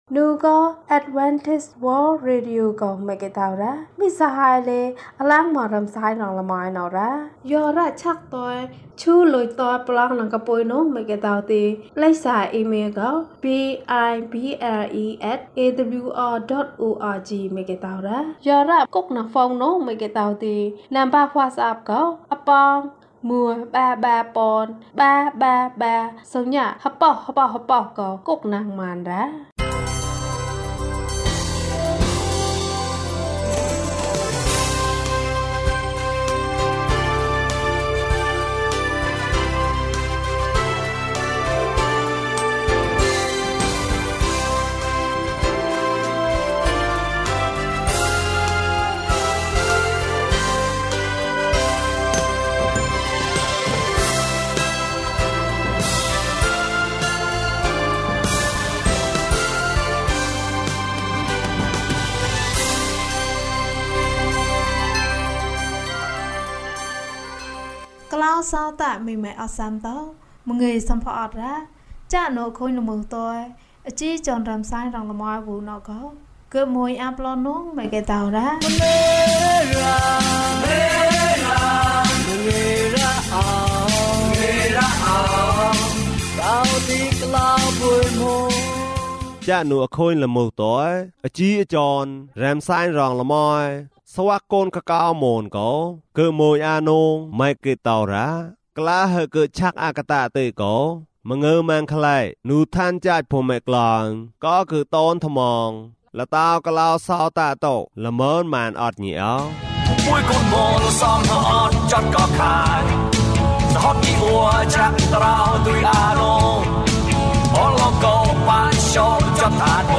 ခရစ်တော်ထံသို့ ခြေလှမ်း။၀၅ ကျန်းမာခြင်းအကြောင်းအရာ။ ဓမ္မသီချင်း။ တရားဒေသနာ။